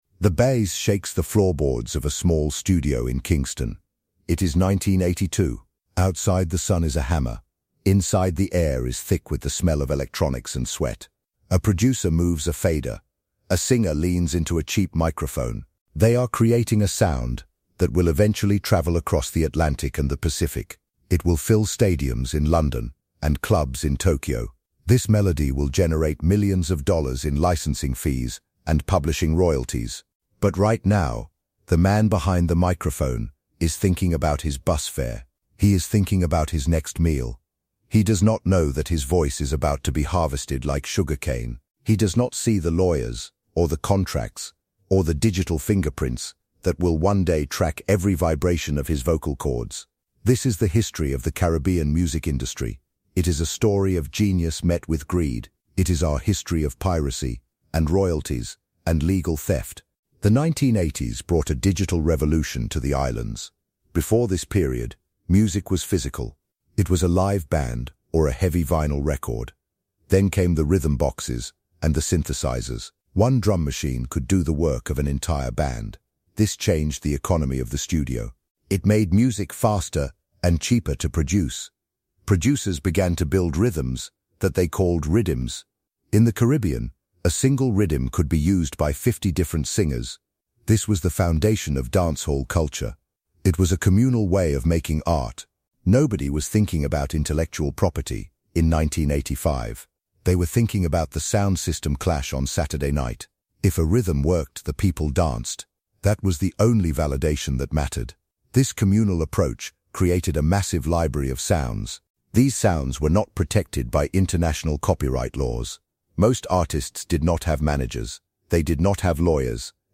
This documentary episode of THE HISTORY OF THE CARIBBEAN exposes the systemic exploitation within the music industry from the nineteen eighties to the present day.